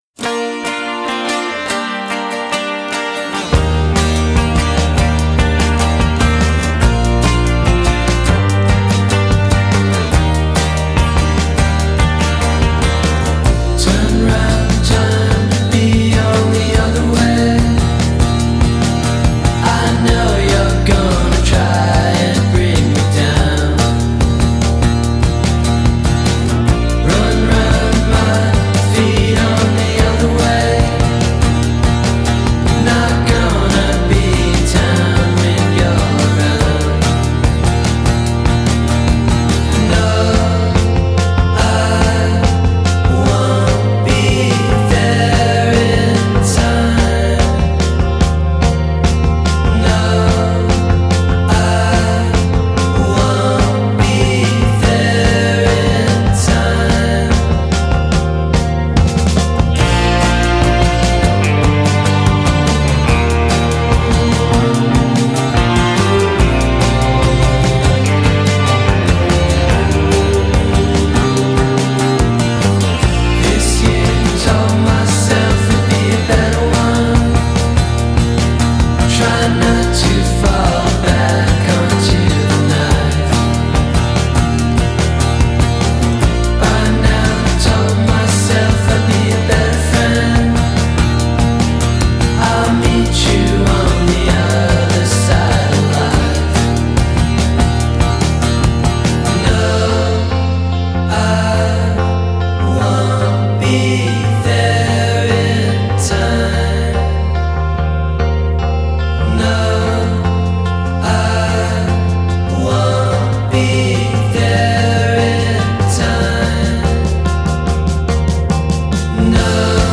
mit geschmeidigem sound